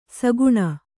♪ saguṇa